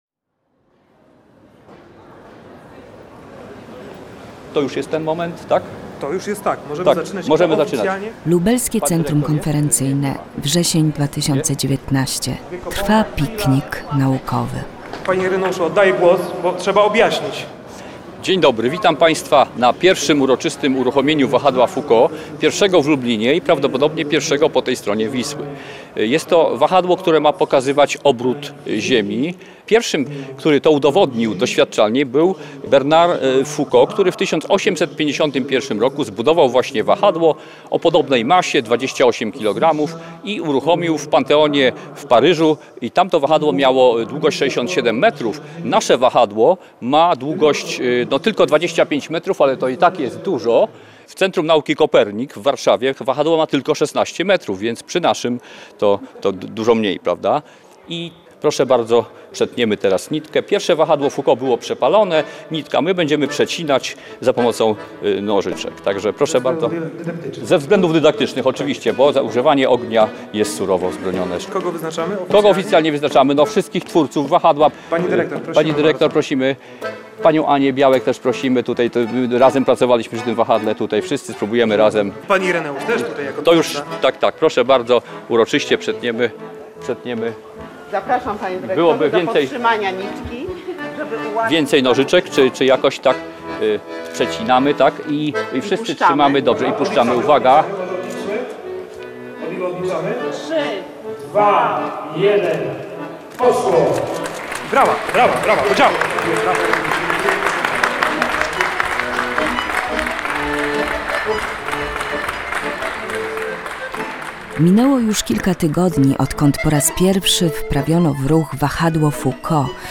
CZYTAJ TAKŻE: Lublin: jedyne takie wahadło w Polsce pomoże w nauce fizyki Fot. archiwum Mistrz z Podwala Tagi: reportaż